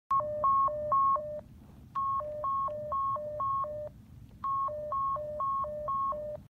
Panic Button Sound Effect Free Download